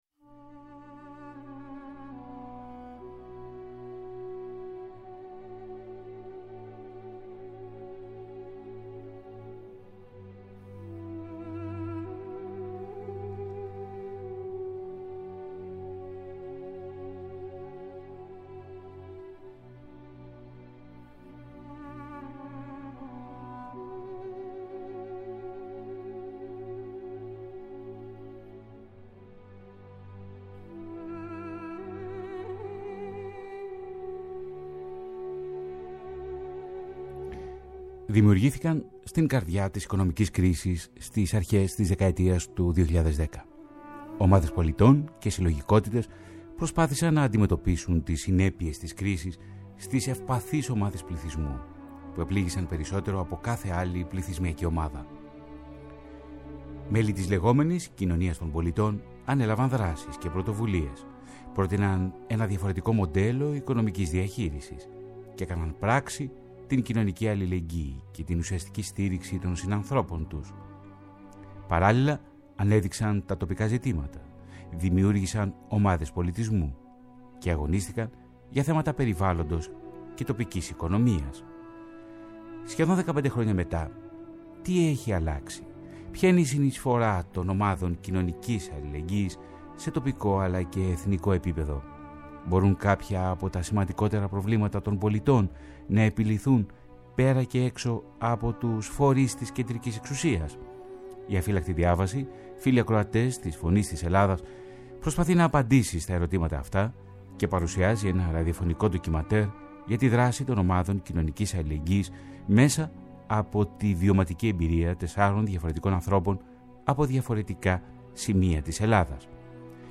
Η «Αφύλαχτη Διάβαση» προσπαθεί να απαντήσει στα ερωτήματα αυτά και παρουσιάζει ένα ραδιοφωνικό ντοκιμαντέρ για τη δράση των ομάδων κοινωνικής αλληλεγγύης μέσα από τη βιωματική εμπειρία τεσσάρων διαφορετικών ανθρώπων από διαφορετικά σημεία της Ελλάδας.